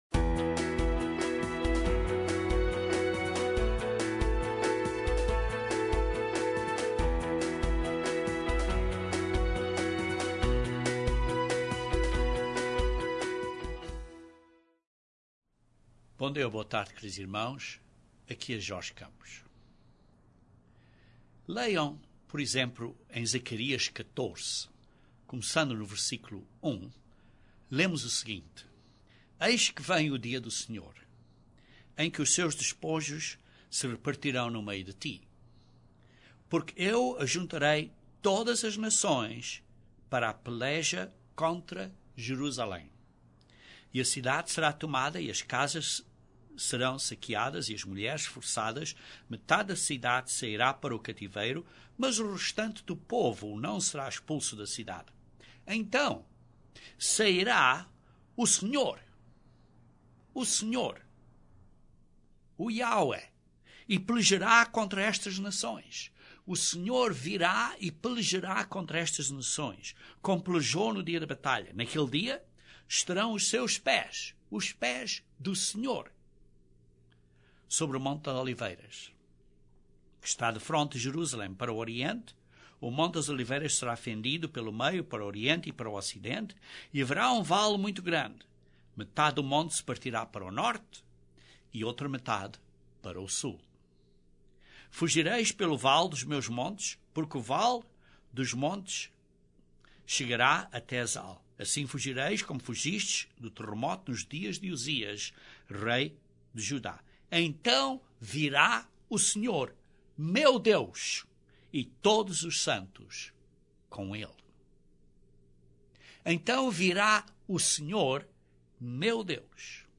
Este estudo Bíblico prova da Bíblia que o retorno do Senhor Jesus Cristo será pessoal, visível, premilenial para vir reinar sobre todas as nações na Terra como Rei dos Reis. Ele continuará seu cargo de Sacerdote, sentar-se-á no trono de David e restaurará todas as coisas, estabelecendo o Reino de Deus para sempre.